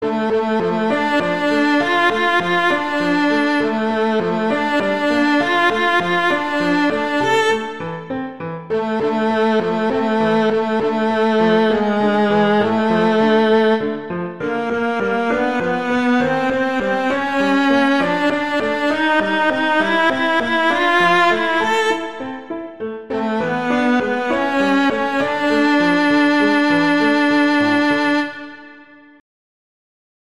arrangement for viola and piano
traditional, christian, inspirational, hymn, children
D minor
♩. = 66 BPM